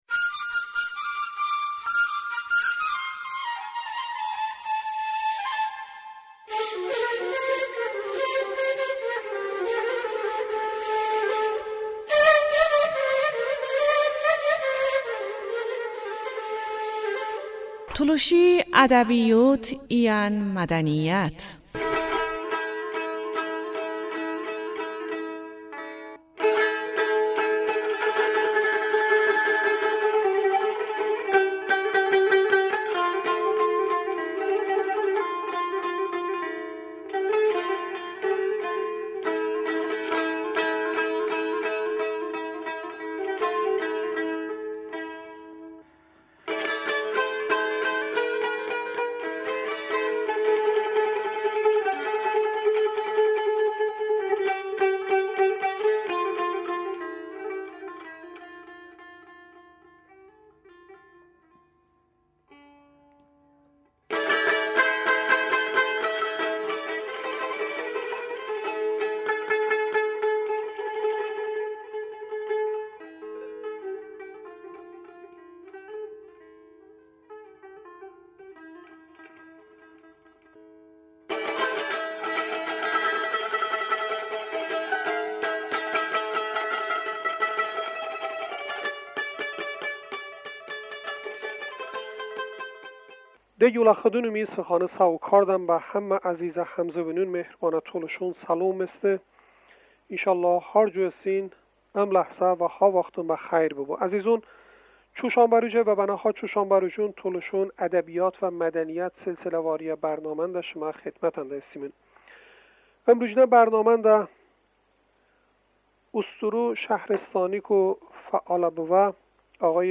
mısohibə